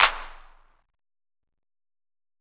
Southside Clapz (25).wav